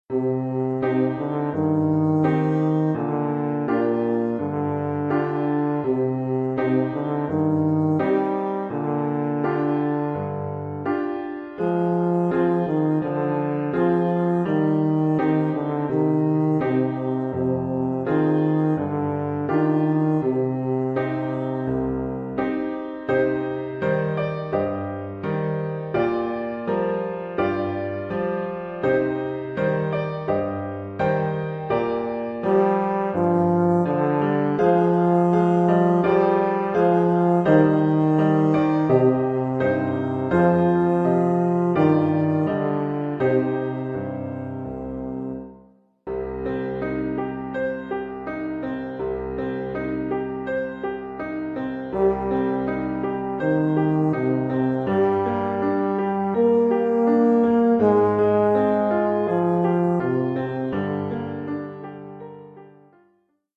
Oeuvre pour saxhorn basse /
euphonium / tuba et piano.
Niveau : débutant (1er cycle).